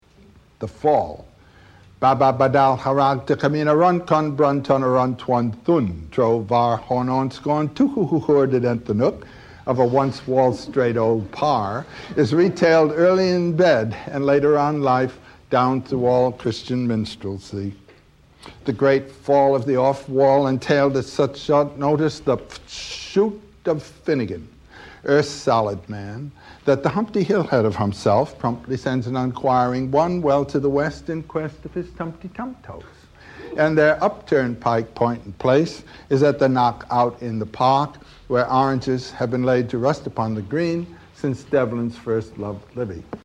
…and there are places, such as FW369.2-21, where his reading is so rushed as to be downright comical: